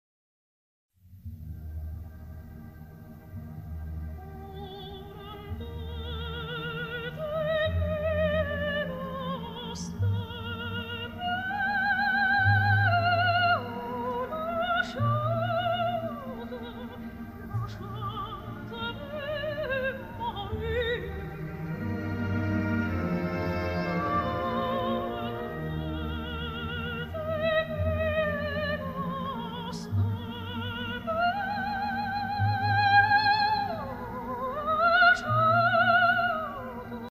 digitally restored